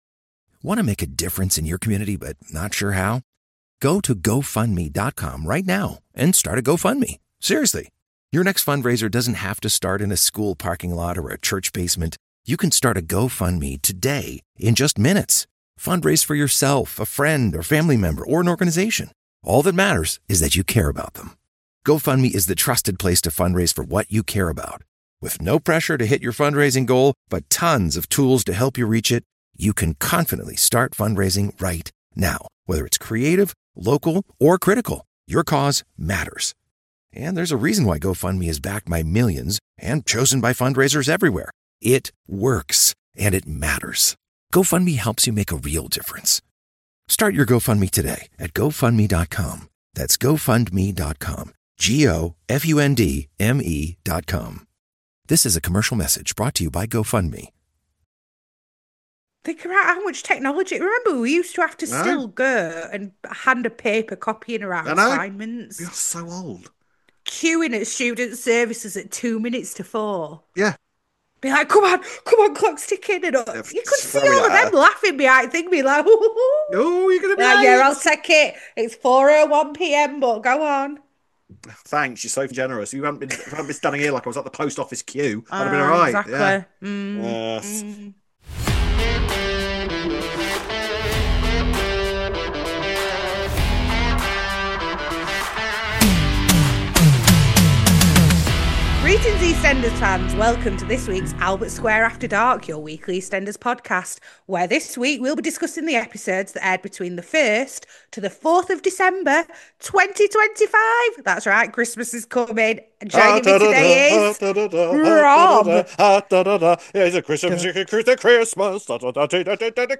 The conversation explores how algorithms exploit emotional vulnerabilities.